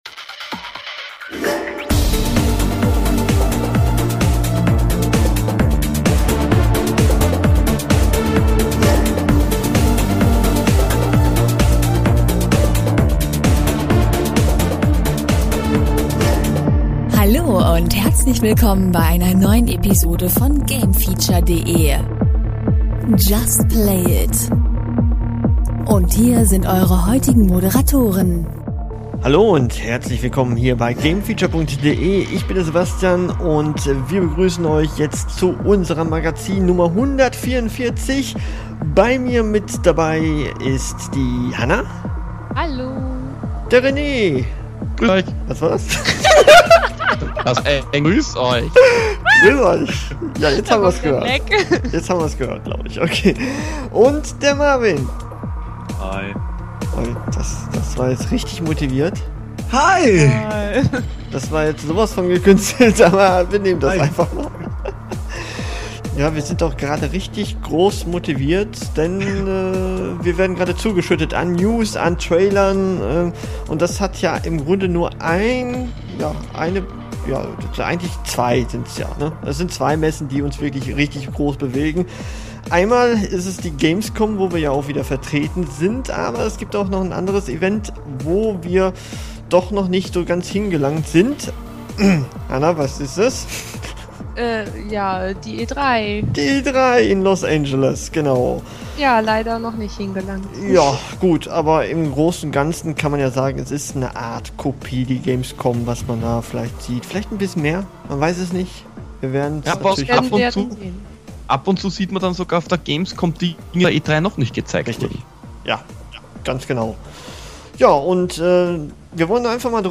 Die große Computer- und Videospiel Messe E3 2014 ist vorbei und wir diskutieren über die Neuankündigungen und die Messe allgemein. Wohin geht die Reise?